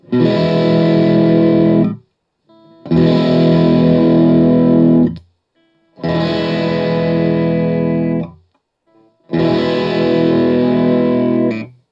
All recordings in this section were recorded with an Olympus LS-10.
Open E Chords
For each recording, I cycle through all four of the possible pickup combinations, those being (in order): neck pickup, both pickups (in phase), both pickups (out of phase), bridge pickup.